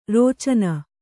♪ rōcana